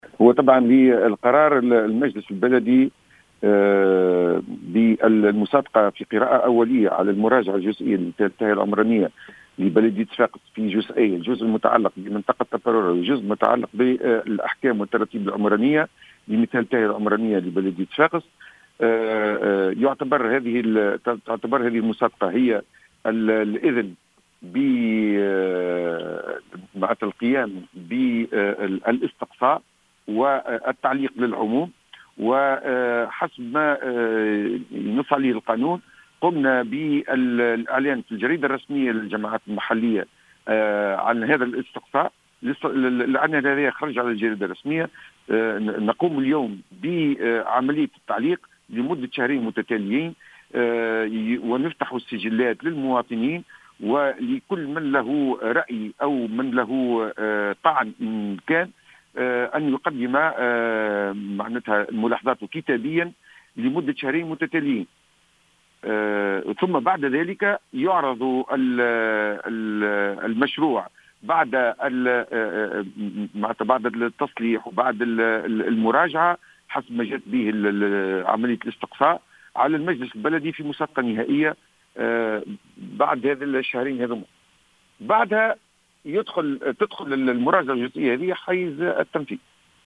أكد رئيس المجلس البلدي بصفاقس منير اللومي، في تصريح للجوهرة أف أم،